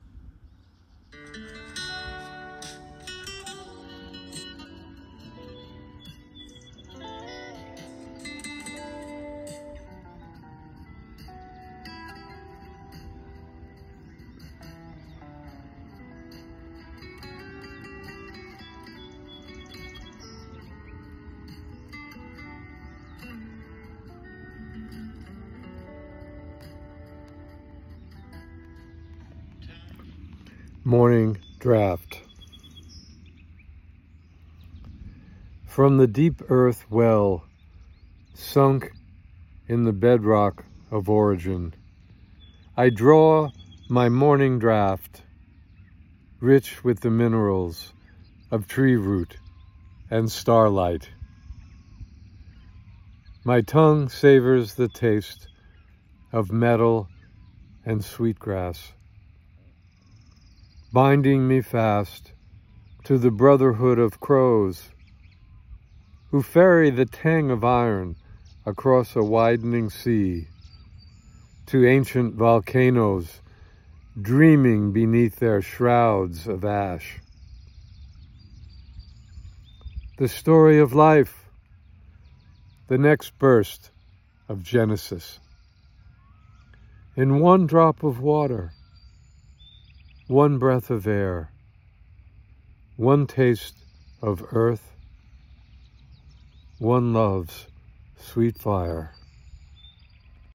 Reading of “Morning Draught” with music by Lukas Nelson.